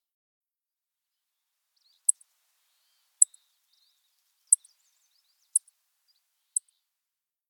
Chipping Sparrow
How they sound: True to its name, this bird’s call sounds like a long trill of evenly spaced chip s. Listen to the Chipping Sparrow.